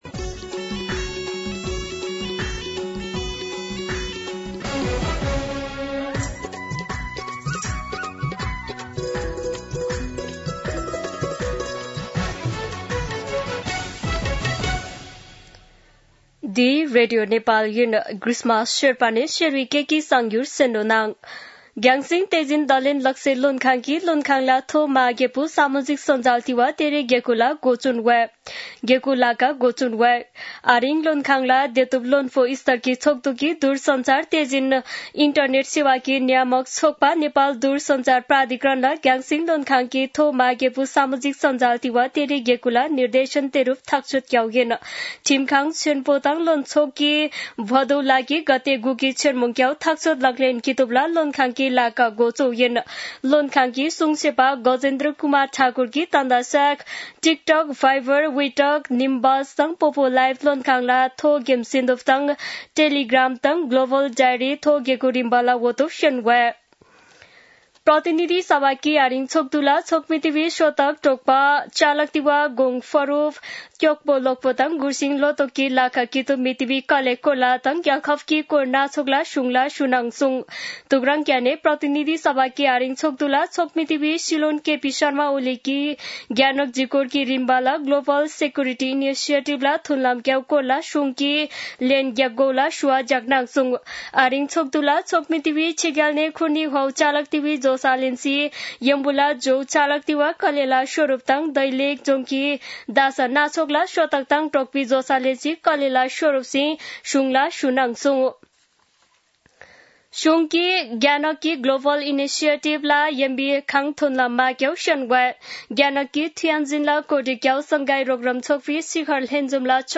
शेर्पा भाषाको समाचार : १९ भदौ , २०८२
Sherpa-News-5-19.mp3